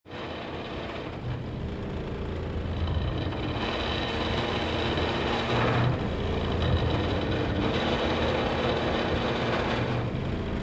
Le symptôme principal est un énorme bruit quand on met en route ça fait trembler les murs du salon.
Voici bruit du climatiseur Altech :
Bruit du climatisation Altech
Euh... vous êtes sure qu'on n'est pas en train de refaire le macadam de votre rue?.... :-))
bruit-clim-altech-3.mp3